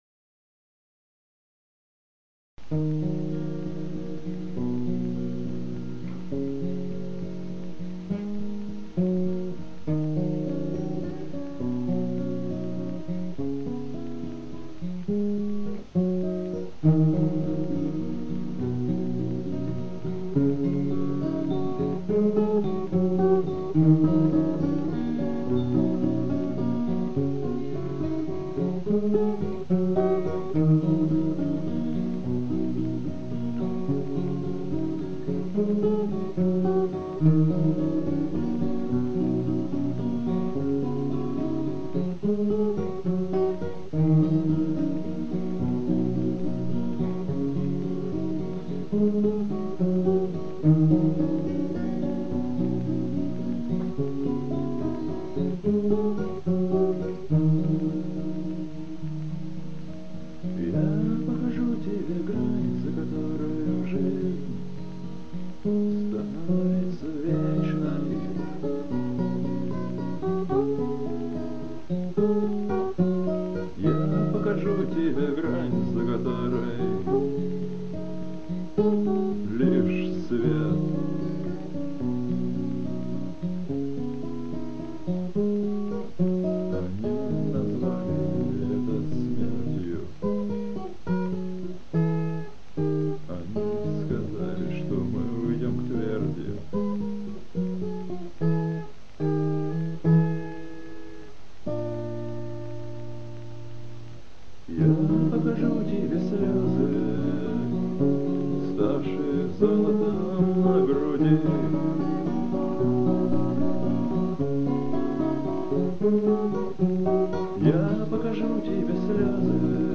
Акустика
вокал, гитара
перкуссия
альт